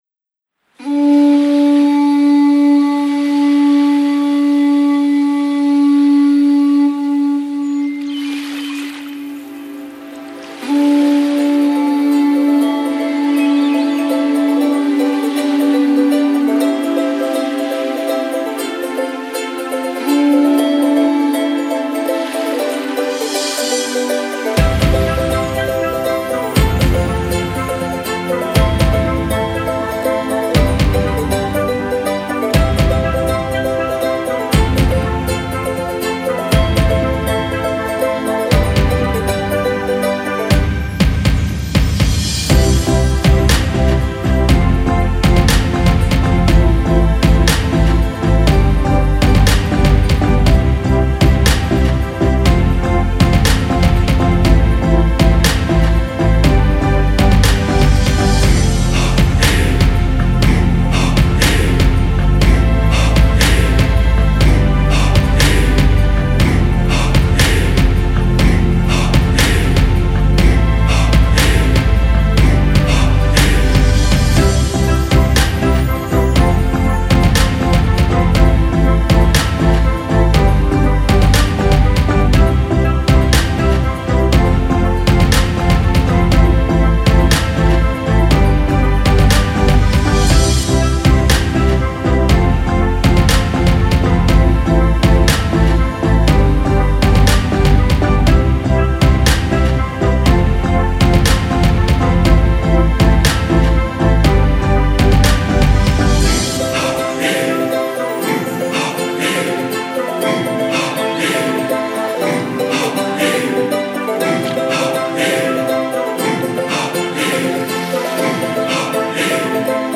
Les pieds dans le sable - version instrumentale (mp3)
Les pieds dans le sable- Instrumental- Version officielle.mp3